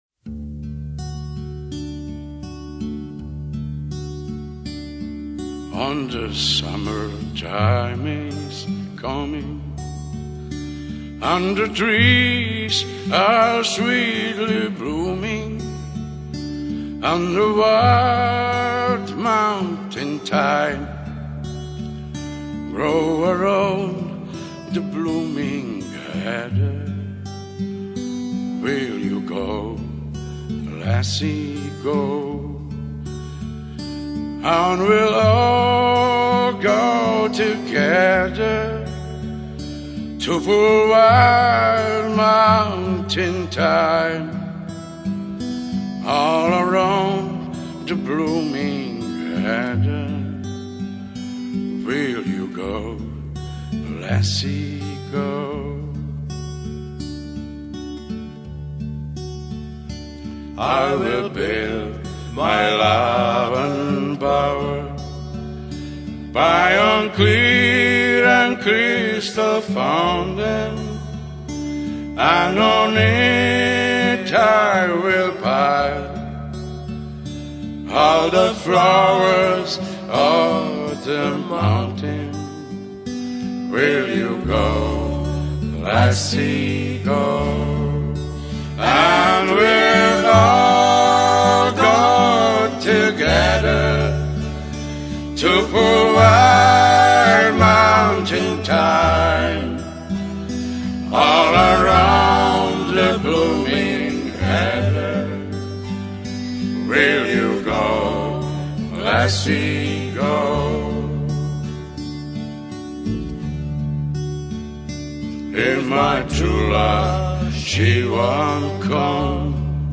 12. traditional